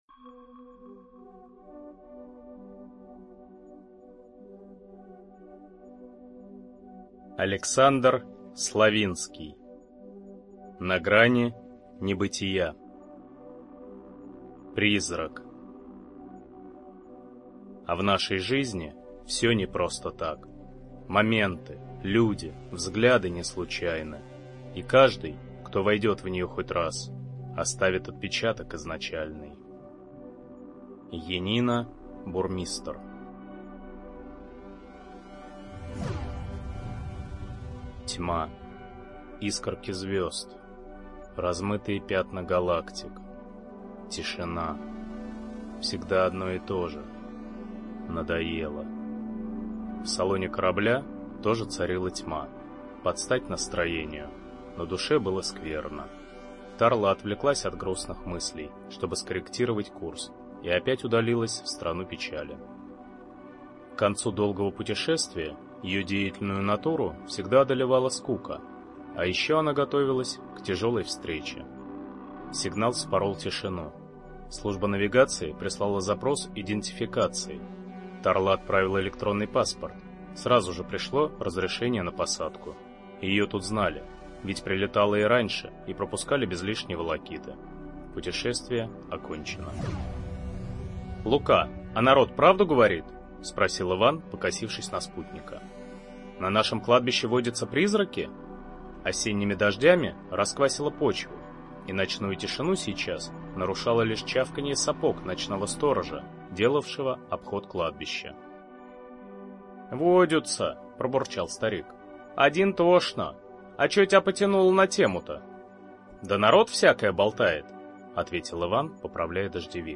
Аудиокнига На грани небытия. Сборник рассказов | Библиотека аудиокниг